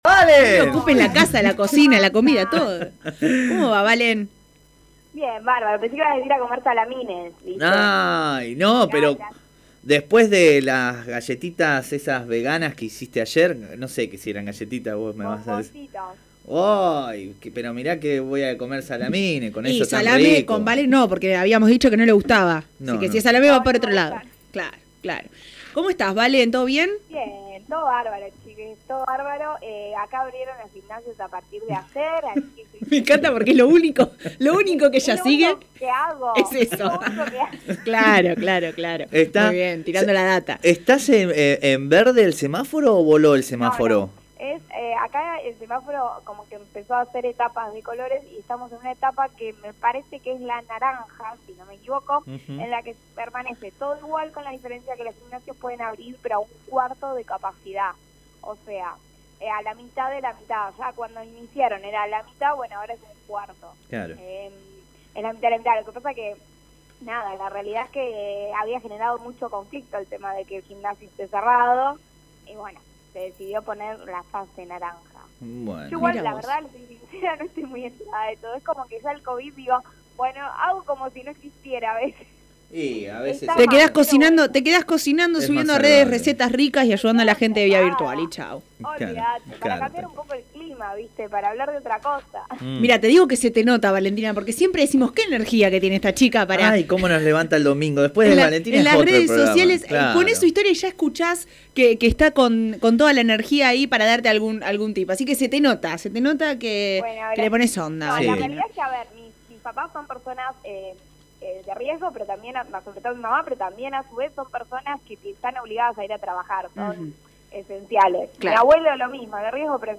columna semanal de nutrición